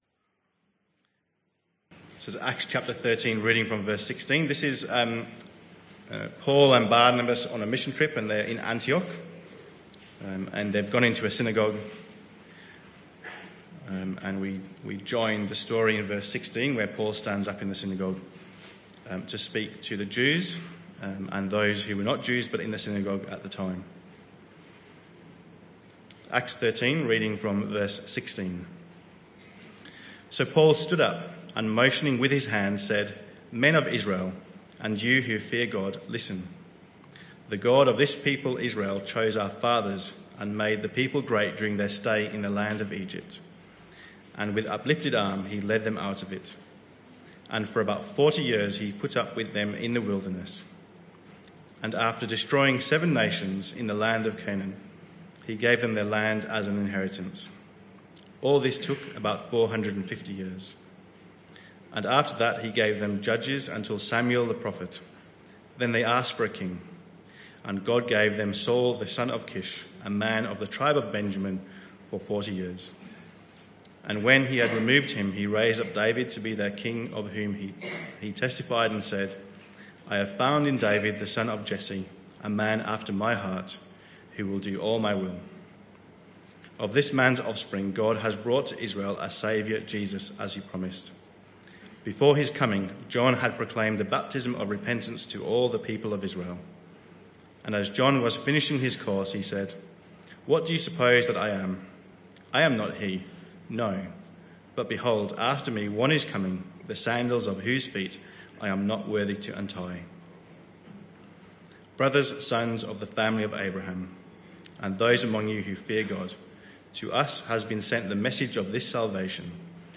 Acts 13:16-48 Service Type: Morning Service Bible Text